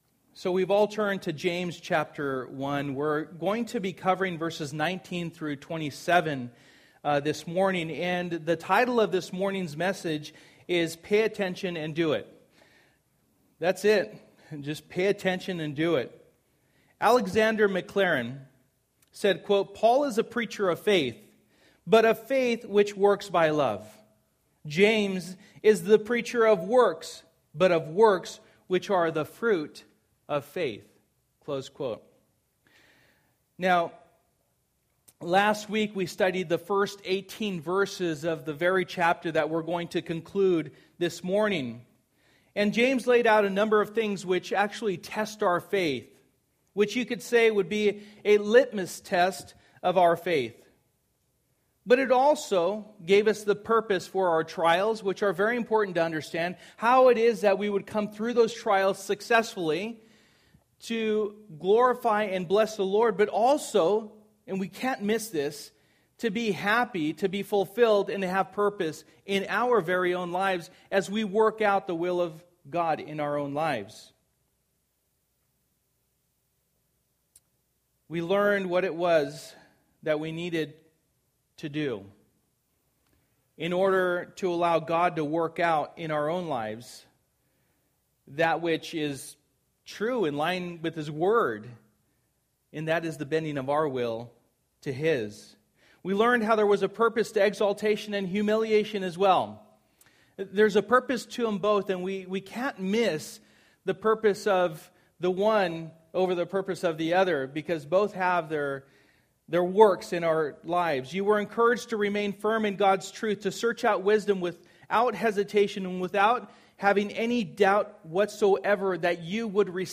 Reflections of a Real Faith Passage: James 1:19-27 Service: Sunday Morning %todo_render% « Your Faith on Trial Overcoming Failures